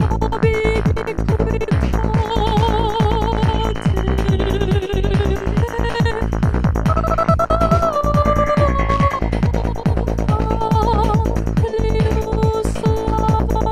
Gated Vocal effects are a cool way of adding extra rhythmic variations and dynamics to you tunes.